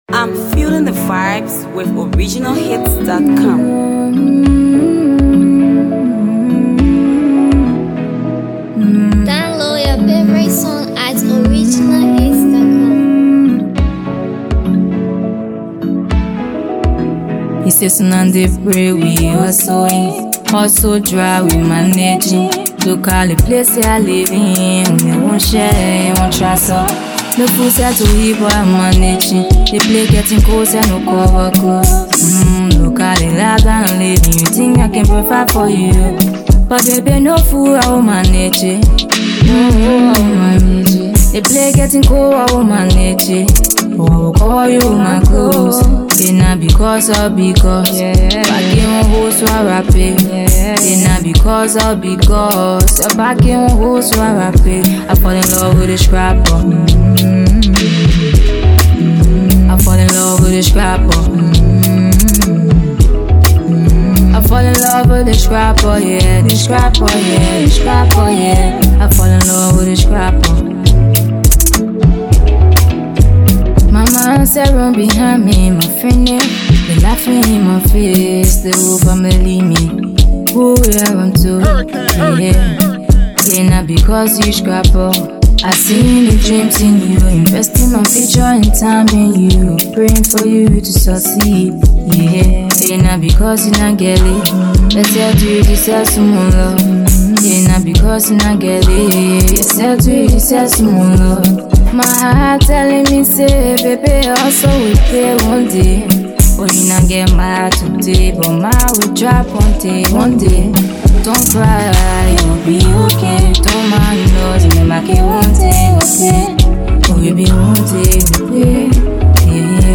Talented Liberian songstress